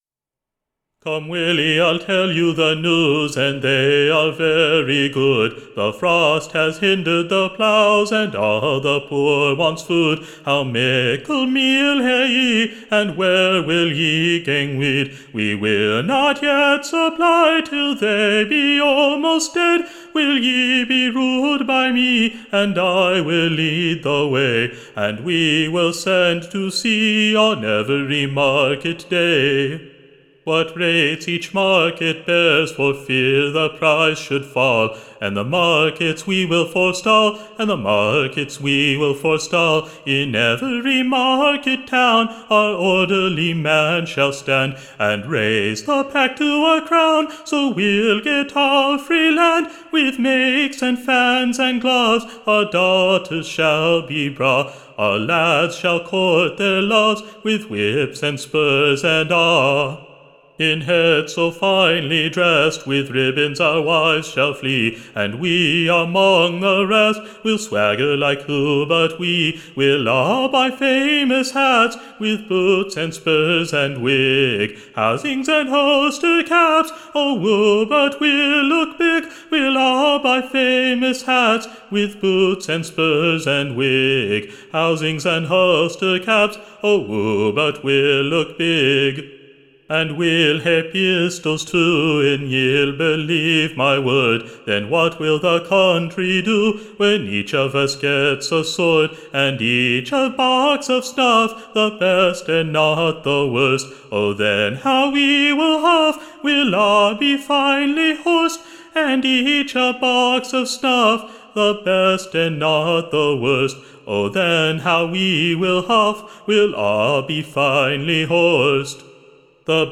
Recording Information Ballad Title THE MEAL MONGERS GARLAND / Containing two excellent new SONGS / Part 1st.